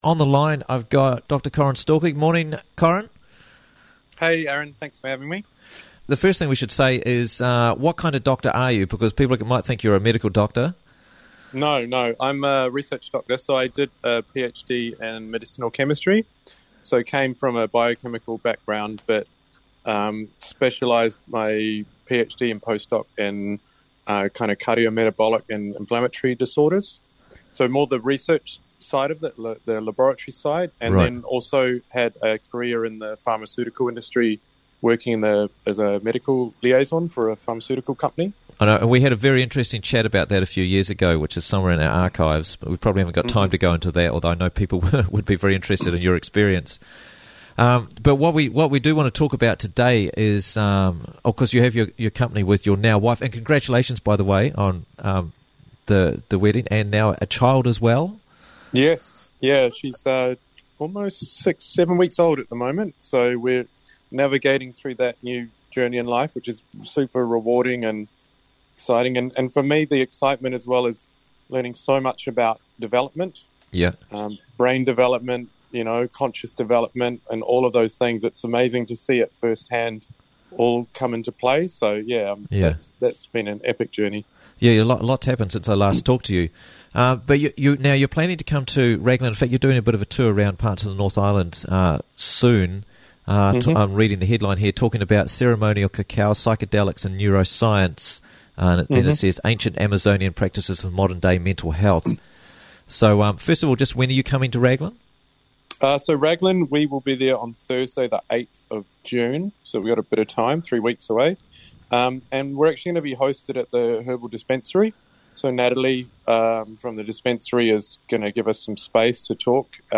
Cacao, Psychedelics & Neuroscience Workshop - Interviews from the Raglan Morning Show